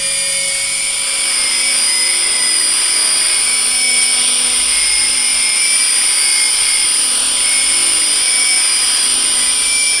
Звук дрона:
drone2.wav